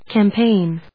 音節cam・paign 発音記号・読み方
/kæmpéɪn(米国英語), kæˈmpeɪn(英国英語)/